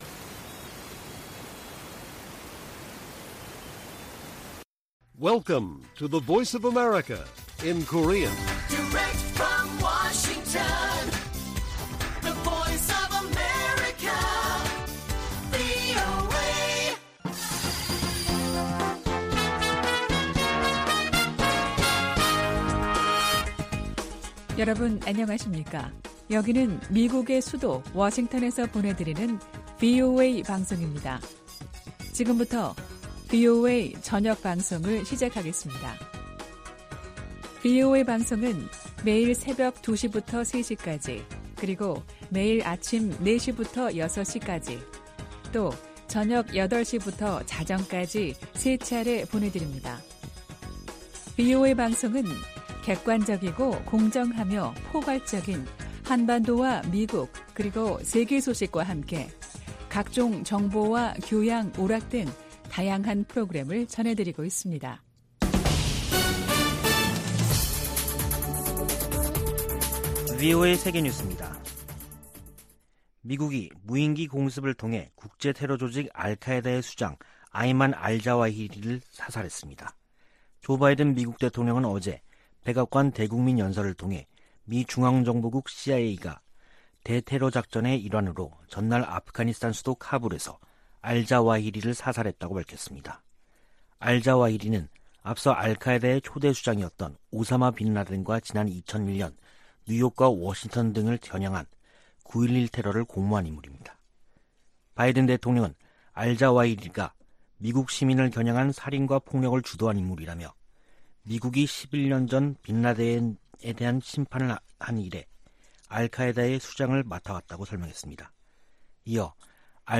VOA 한국어 간판 뉴스 프로그램 '뉴스 투데이', 2022년 8월 2일 1부 방송입니다. 조 바이든 미국 대통령은 핵확산금지조약(NPT) 평가회의를 맞아 비확산 체제 준수와 지지 입장을 재확인했습니다. 토니 블링컨 미 국무장관은 NPT 평가회의에서, 조약이 가중되는 압박을 받고 있다며 북한과 이란의 핵 개발 문제를 비판했습니다. 미국과 한국, 일본이 실시하는 북한 탄도미사일 탐지 추적 연합훈련인 ‘퍼시픽 드래곤’에 캐나다와 호주도 참여한다고 밝혔습니다.